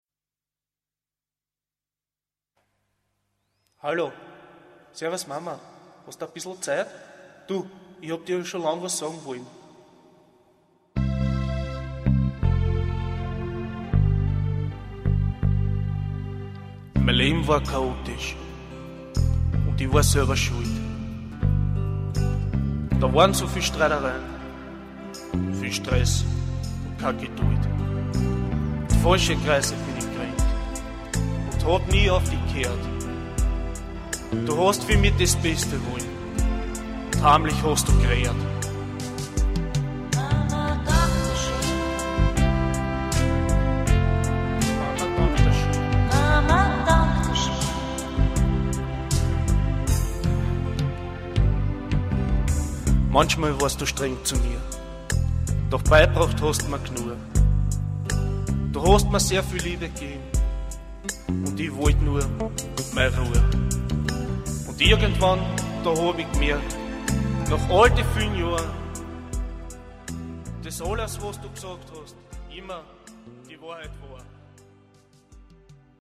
SINGEN
Mit viel Schweiß nahmen wir die schöne Ballade, die ich getextet hatte, auf.